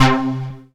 Synth Stab 21 (C).wav